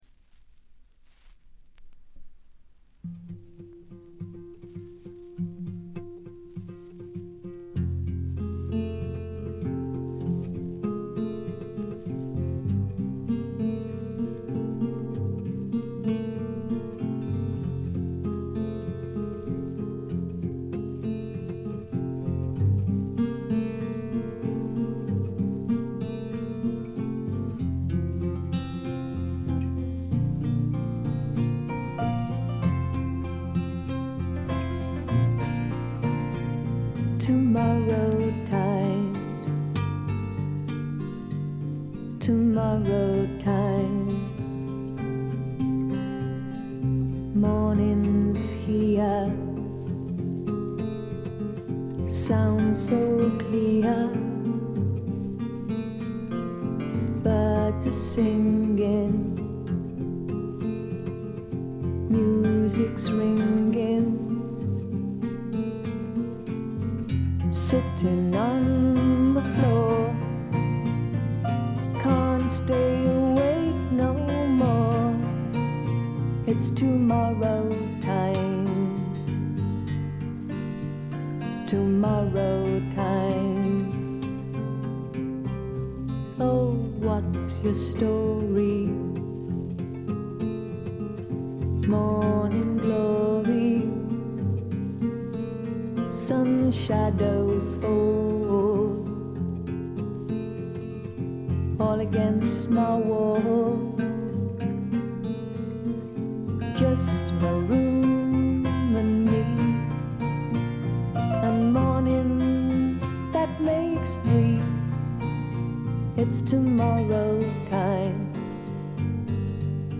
ほとんどノイズ感無し。
音の方はウッドストック・サウンドに英国的な香りが漂ってくるという、この筋の方にはたまらないものに仕上がっています。
試聴曲は現品からの取り込み音源です。